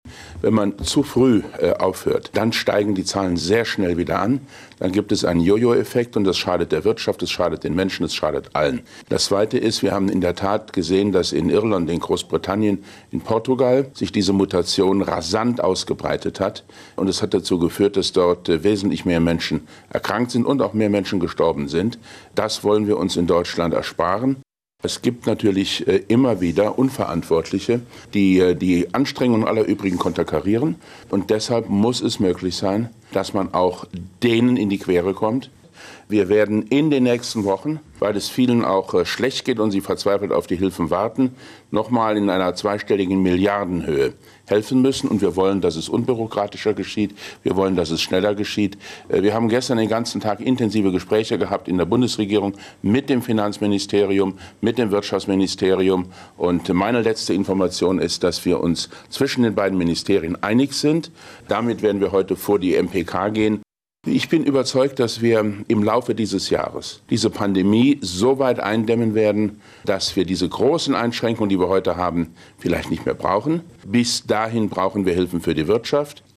Einen O-Ton zu den Coronahilfen von Wirtschaftsminister Altmaier finden Sie hier: